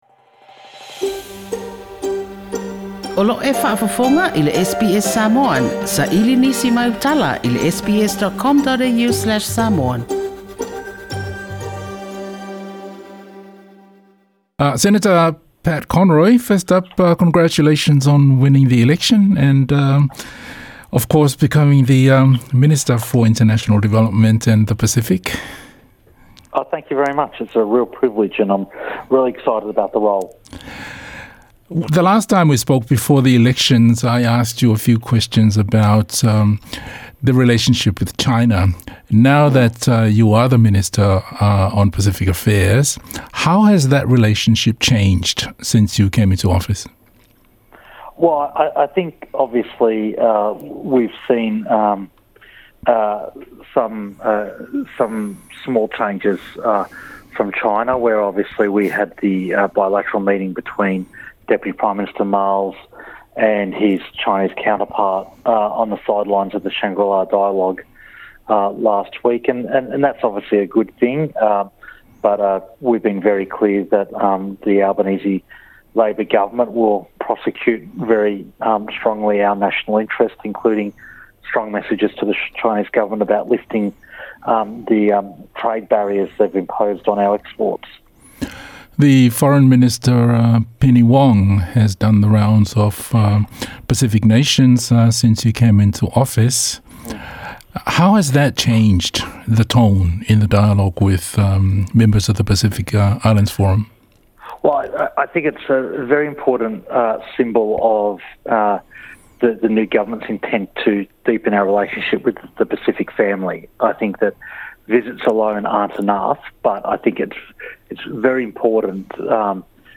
This is our first interview with Senator Pat Conroy, the minister for International Development and the Pacific since the federal election in May.